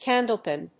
アクセント・音節cándle・pìn